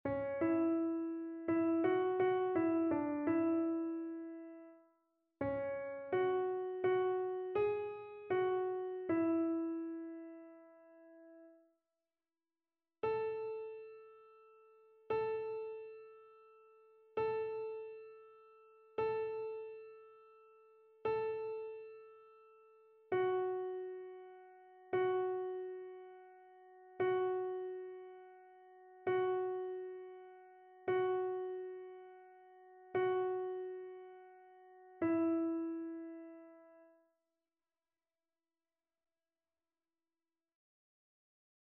Chœur
SopranoAlto
annee-b-temps-ordinaire-14e-dimanche-psaume-122-soprano.mp3